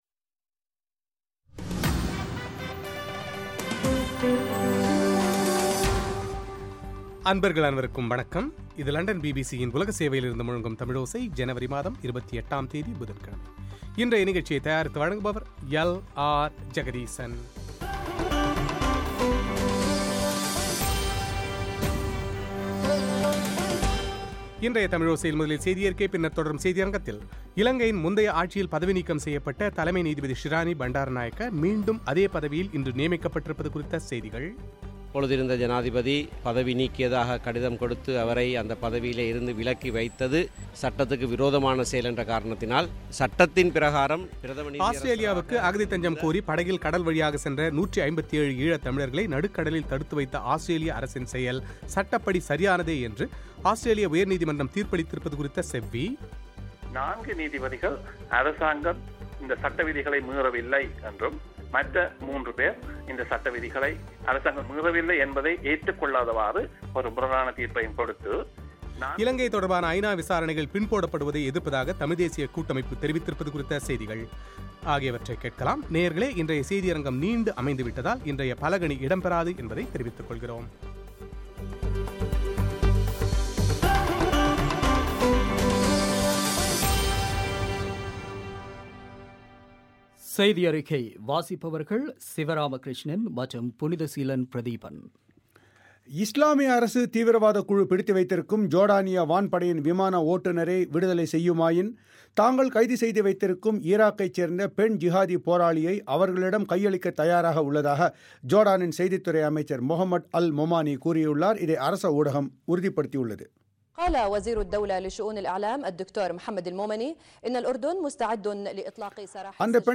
பிரத்யேக பேட்டி